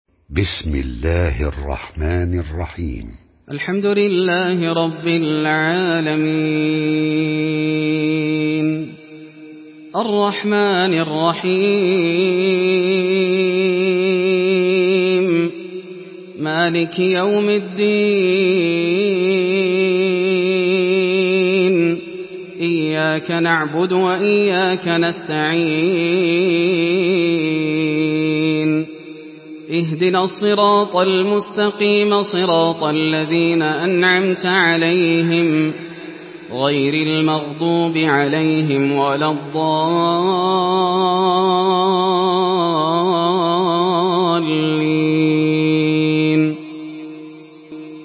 Surah Fatiha MP3 by Yasser Al Dosari in Hafs An Asim narration.
Murattal